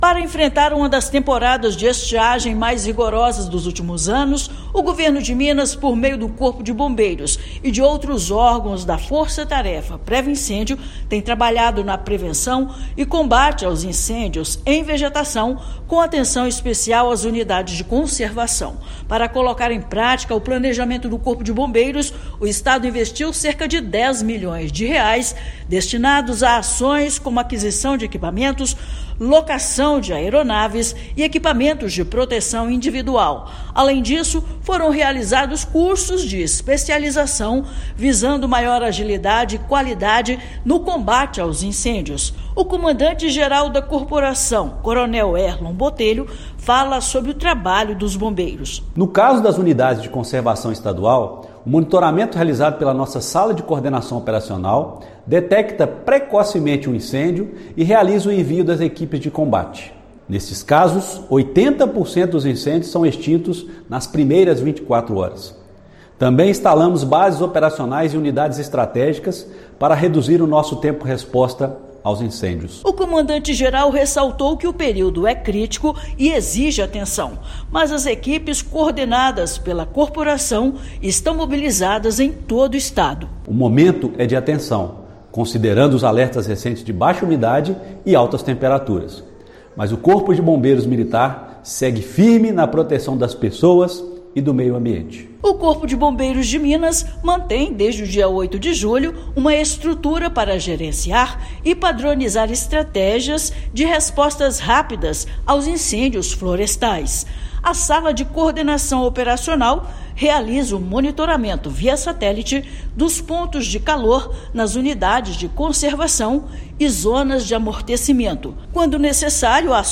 Gerenciamento permite que mais de 80% dos incêndios sejam eliminados no primeiro dia. Ouça matéria de rádio.